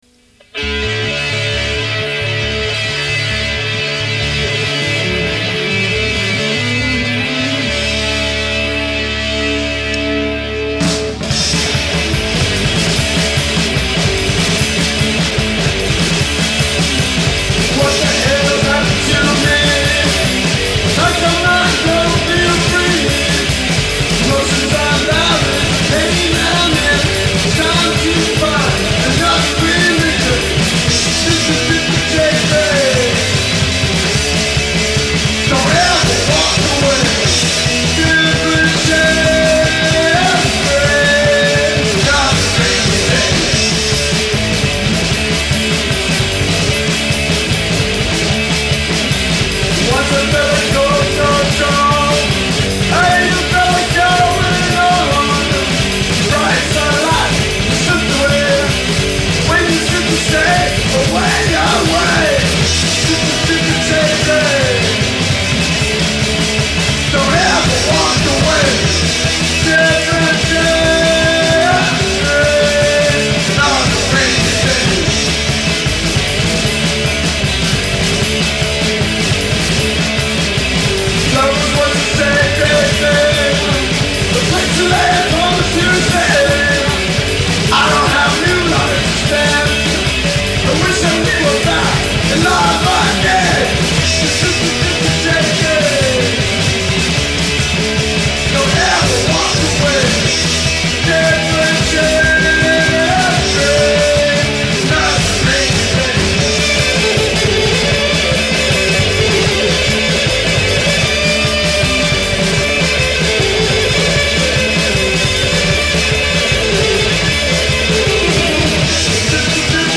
These songs are from their demo tape.